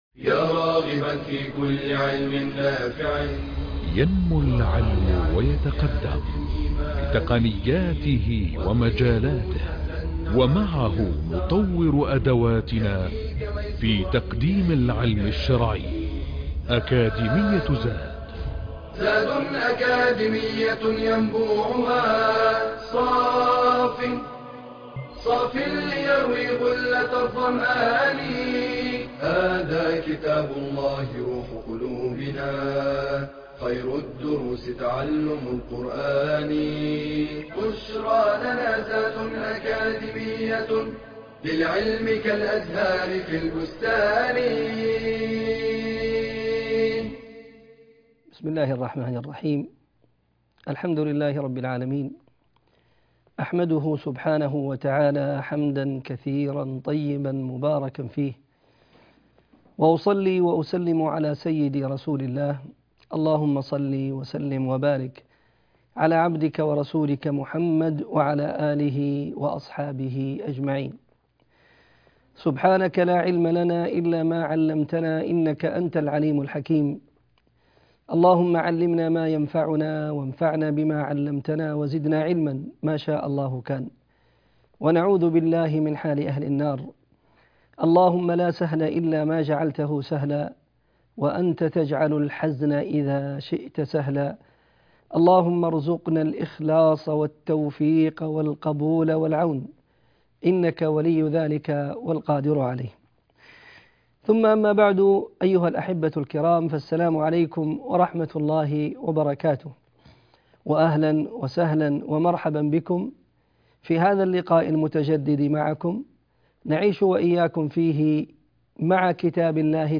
المحاضرة الخامسه